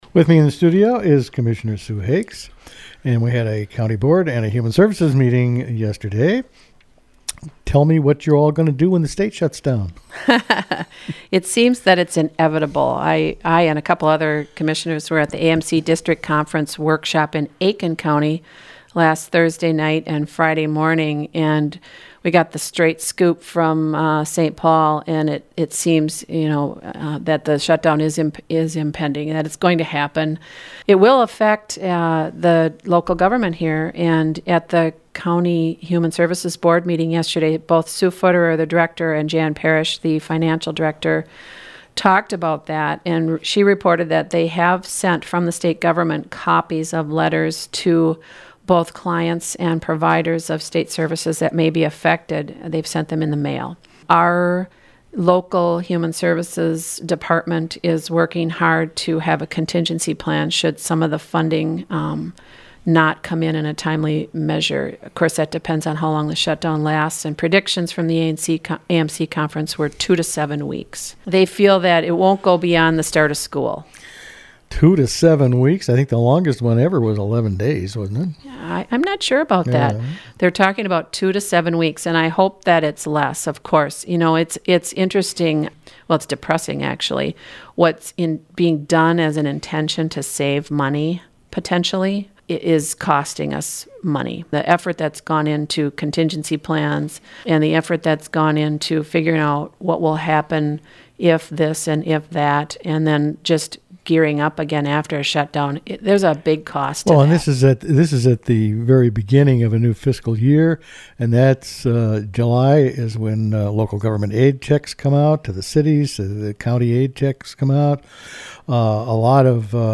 The complete interview with Commissioner Hakes is also attached.